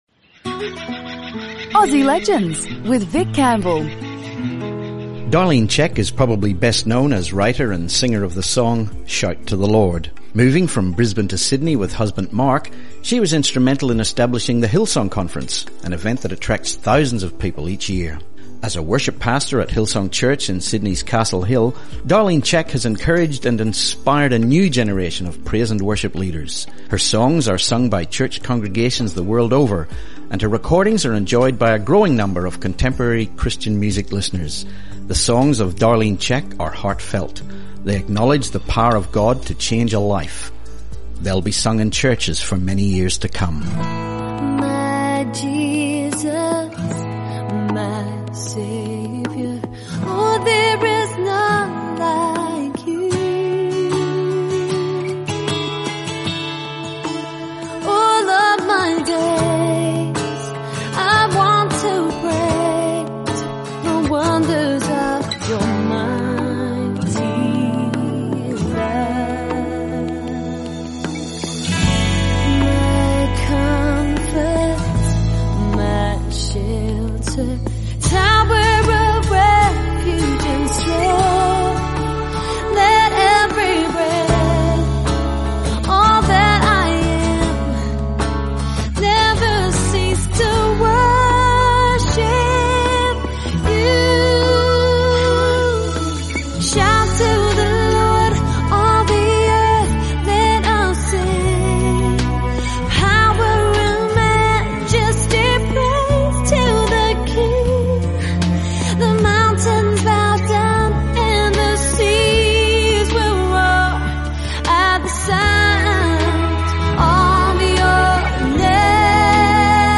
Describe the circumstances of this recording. Broadcast on Southern FM’s “Songs of Hope” on 9 March 2014.